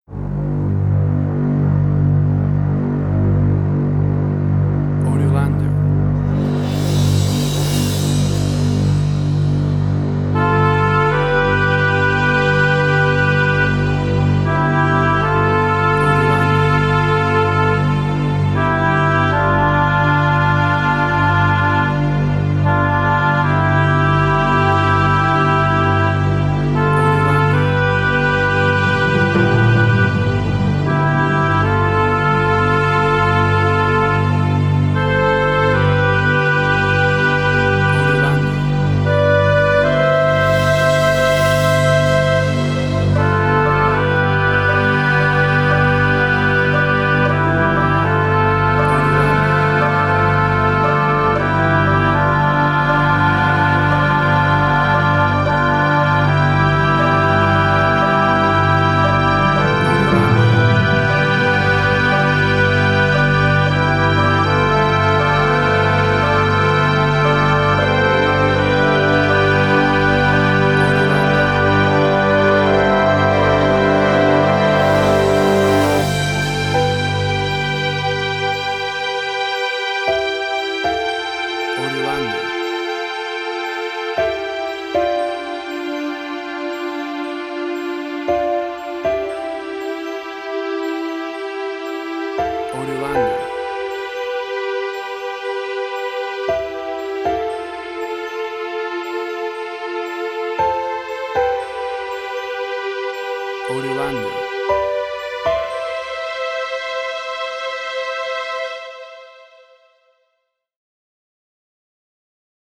Indie Quirky.
Tempo (BPM): 118